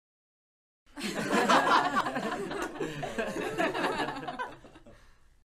Laughter Small Crowd Sound Button - Free Download & Play
Sound Effects Soundboard154 views